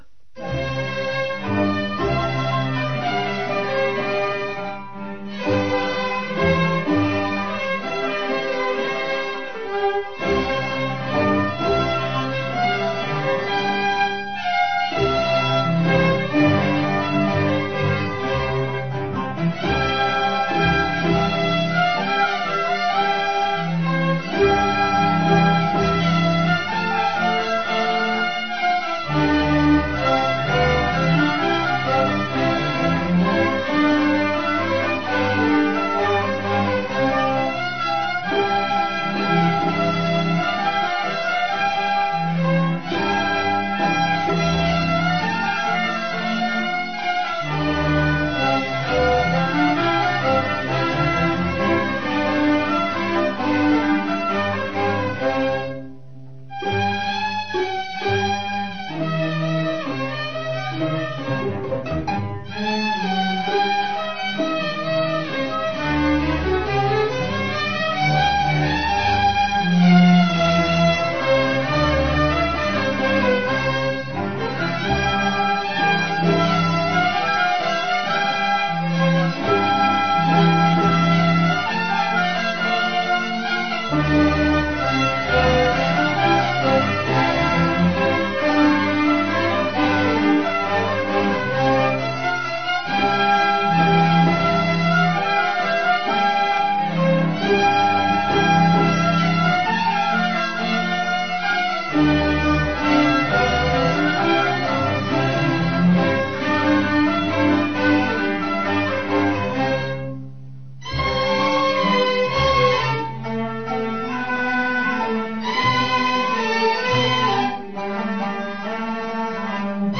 على مقام العجم ، و قد اعتمد فيه طبعاً التوزيع الهارموني